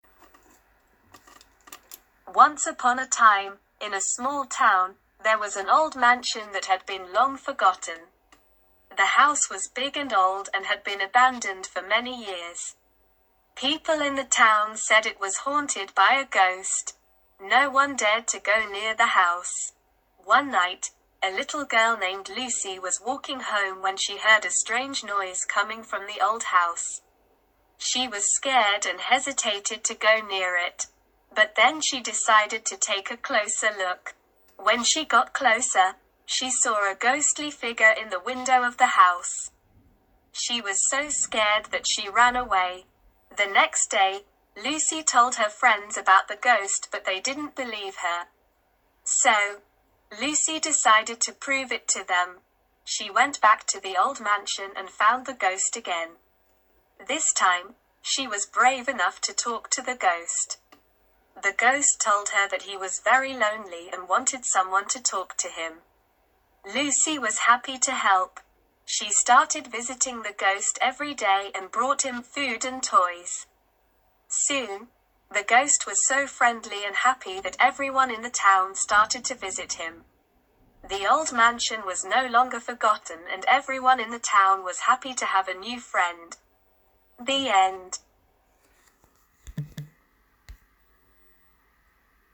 Аудирование.